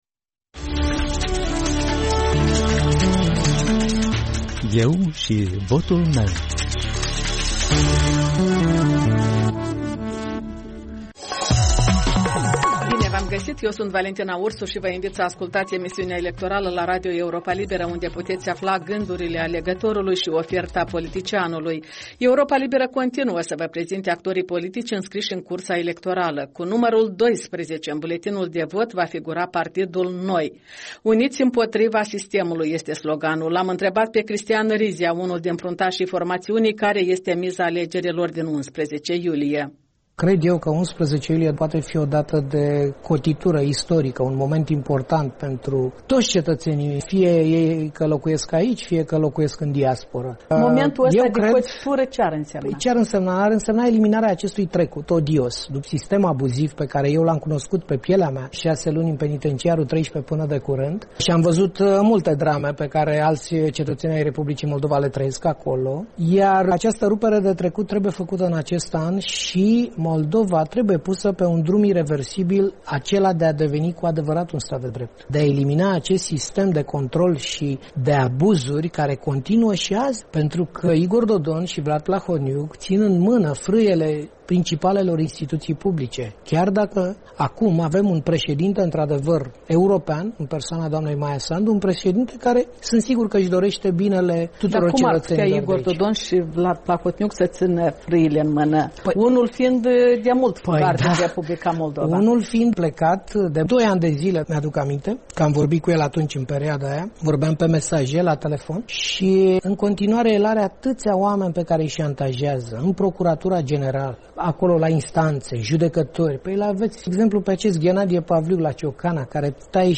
Radio Europa Liberă acoperă noul ciclu electoral cu interviuri, comentarii, analize și reportaje video din campania electorală.